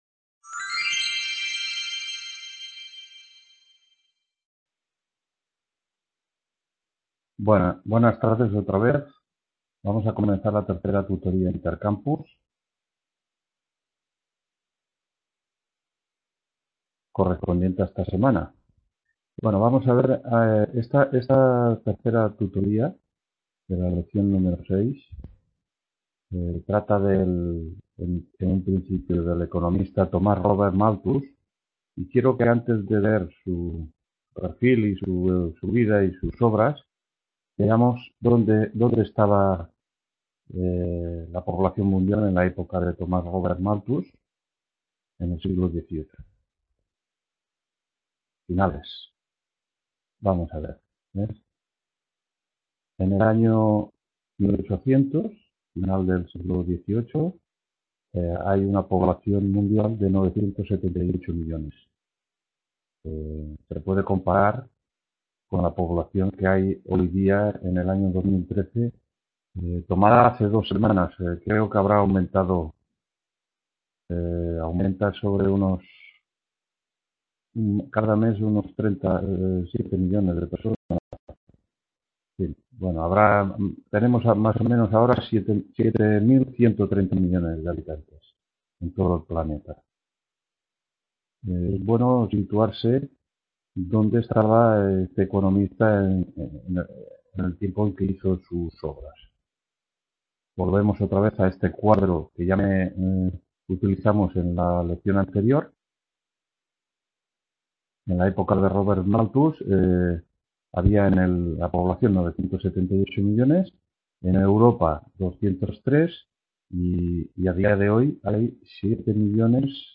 3ª TUTORÍA INTERCAMPUS HISTORIA DEL PENSAMIENTO… | Repositorio Digital